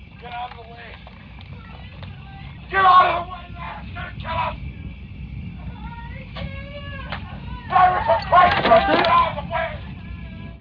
Ripley hears the panicked cries of Parker and Lambert through the communication lines.